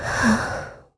Ripine-Vox_Sigh_kr.wav